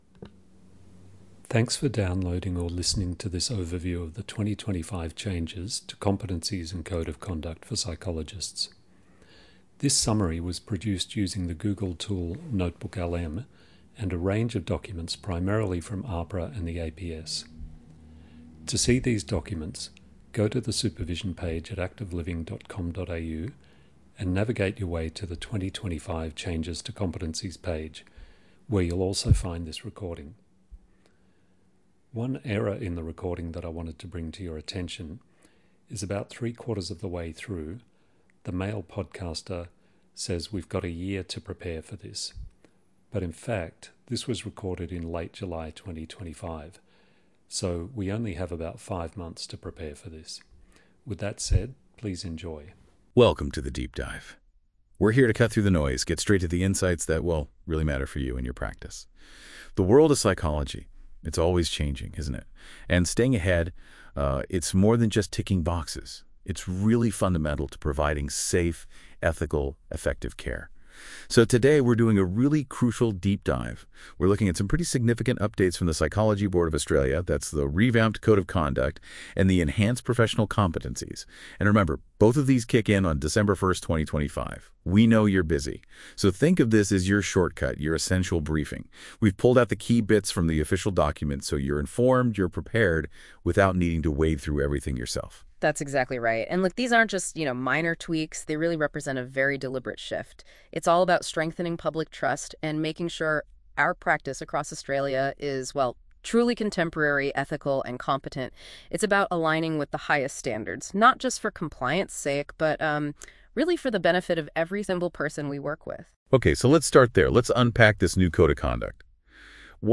Click here to hear a discussion of the changes and what they mean for you.